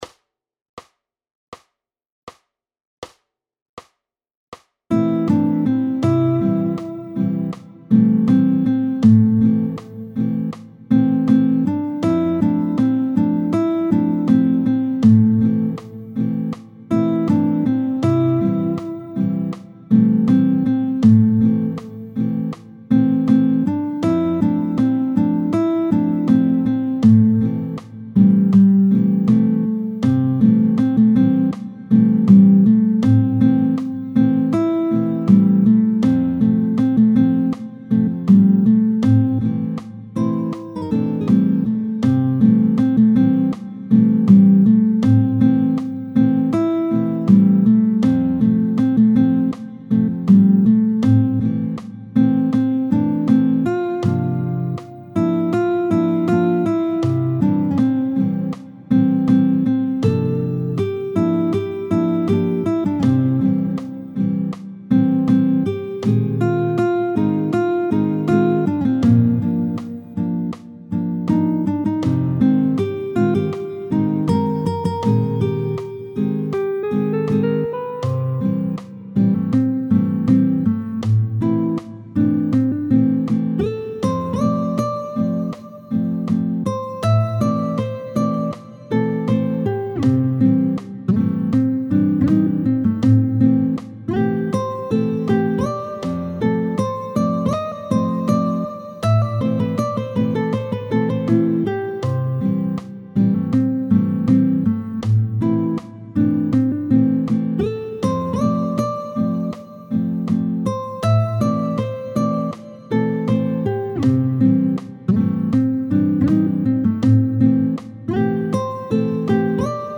tempo 80